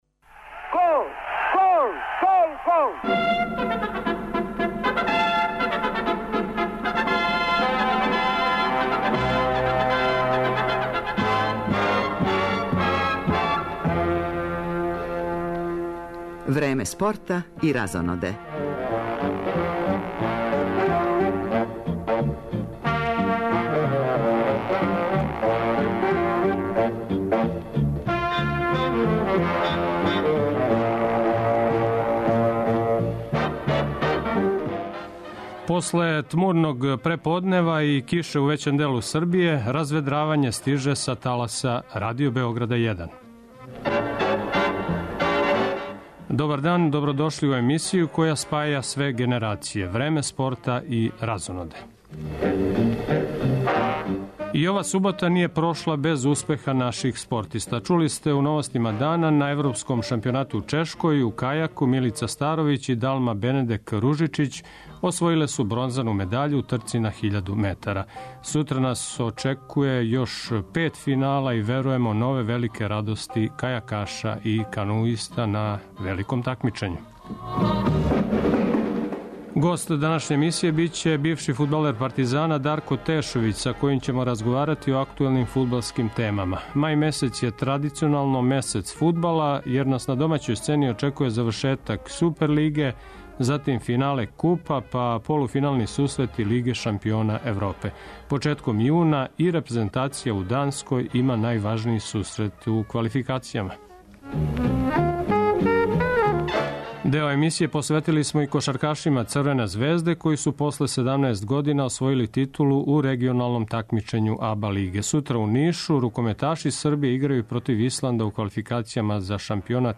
Чућемо утиске играча - који су клубу са Малог Калемегдана донели титулу после 17 година.